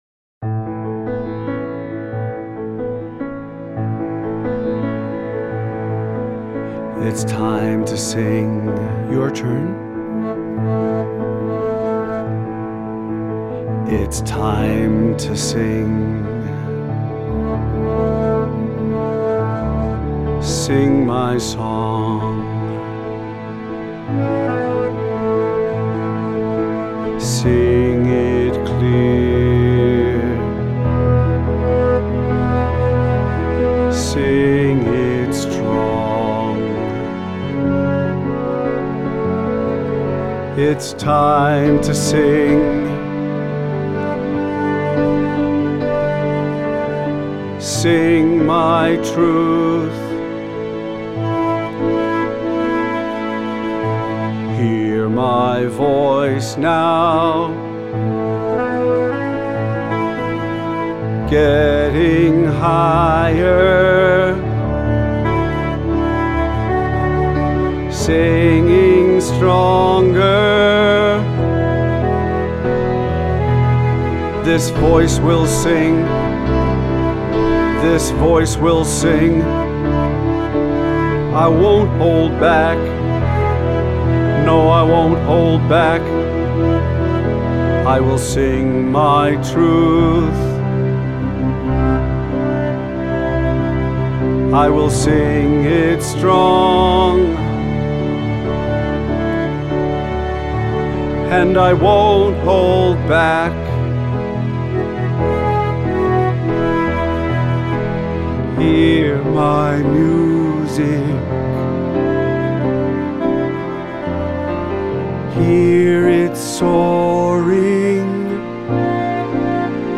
Affirmation w Soft Flute